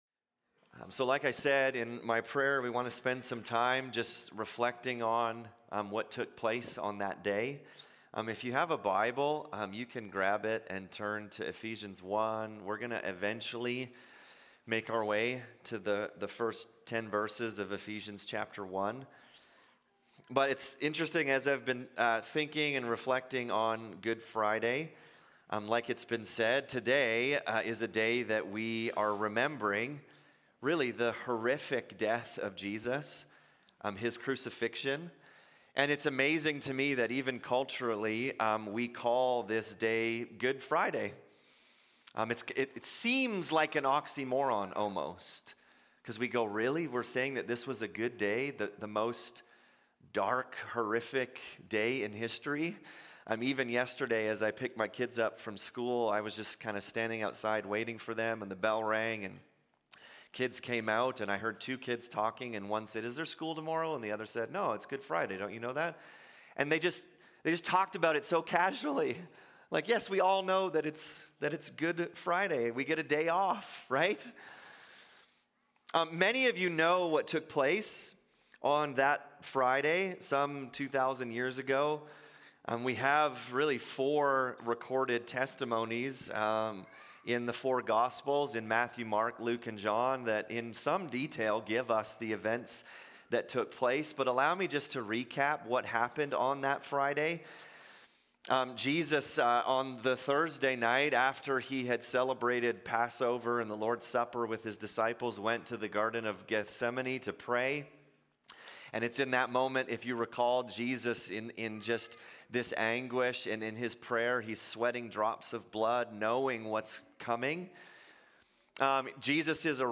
Sermons | North Peace MB Church